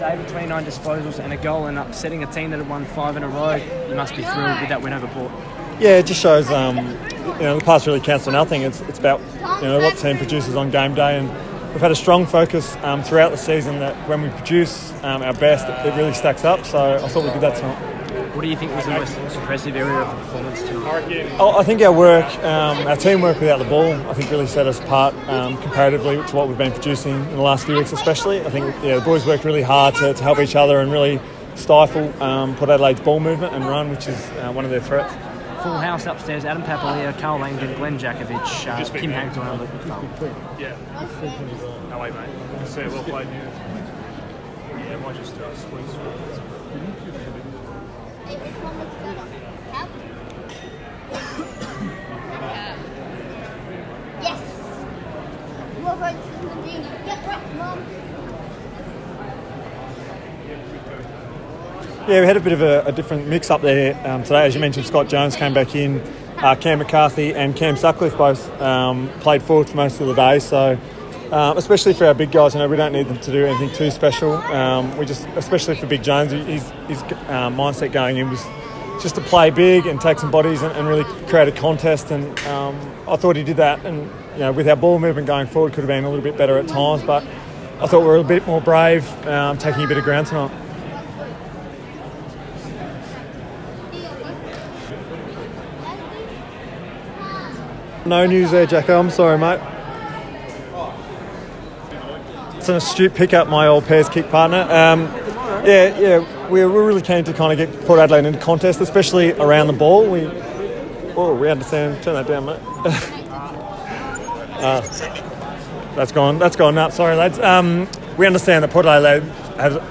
David Mundy chats to 6PR after the round 17 win over Port Adelaide